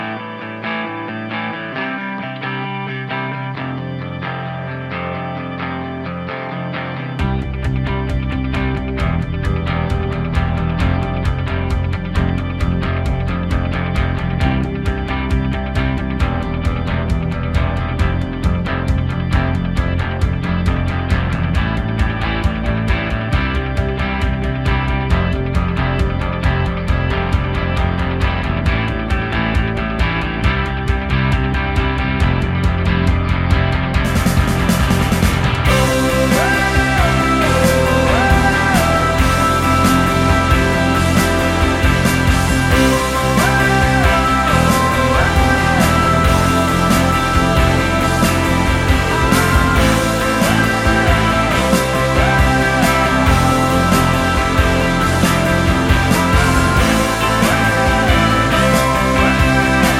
Medley Rock